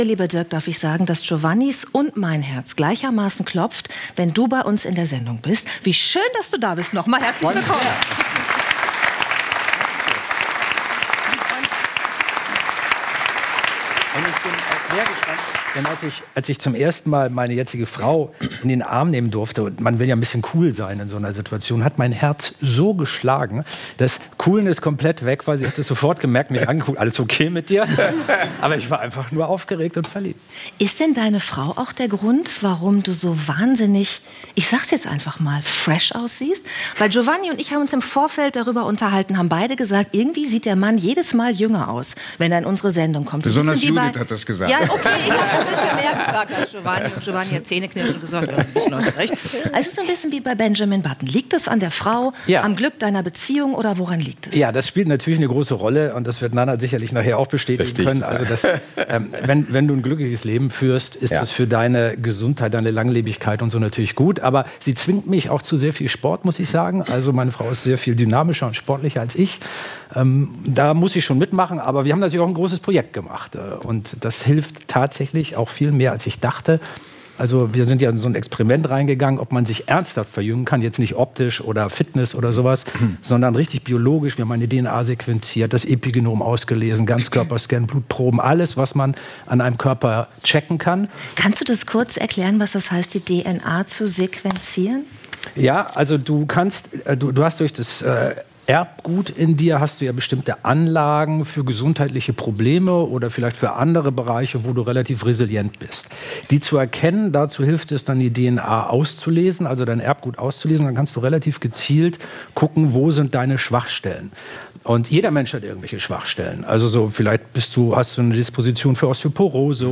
Dirk Steffens – Wissenschaftsjournalist ~ 3nach9 – Der Talk mit Judith Rakers und Giovanni di Lorenzo Podcast